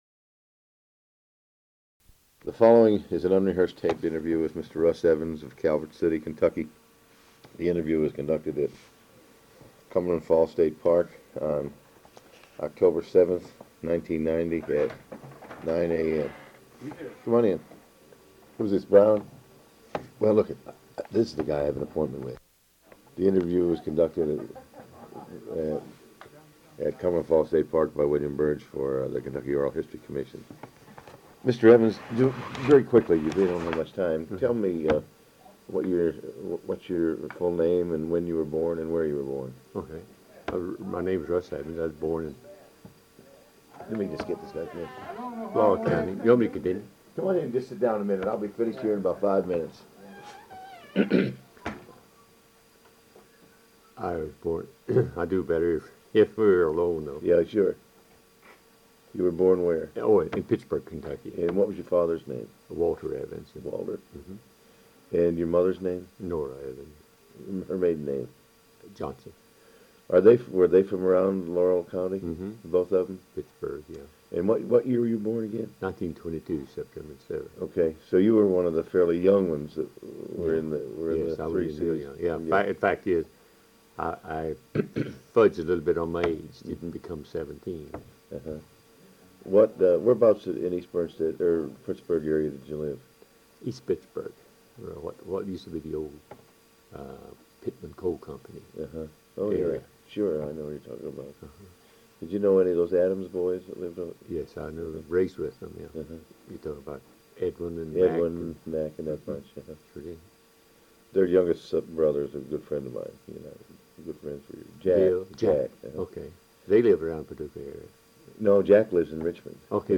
Oral History Interview
C.C.C. (Civilian Conservation Corps) Oral History Project